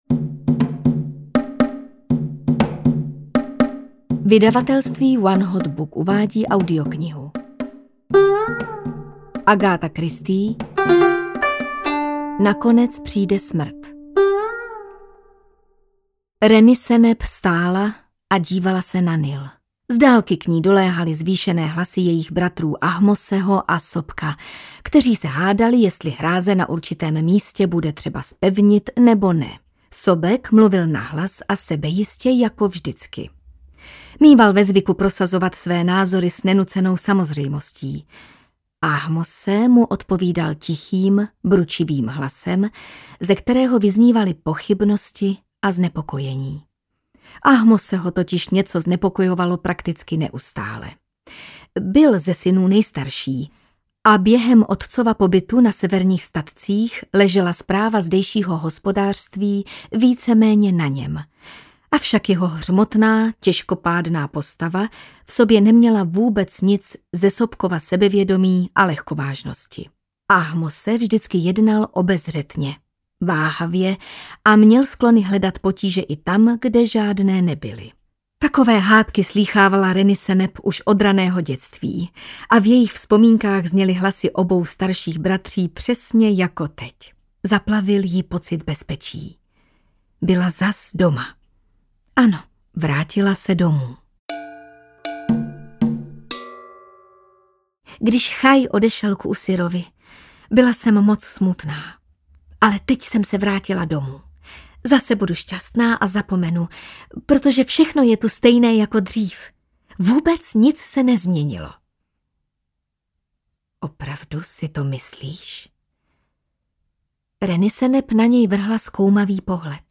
Nakonec přijde smrt audiokniha